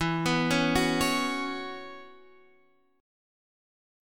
Bsus2/E Chord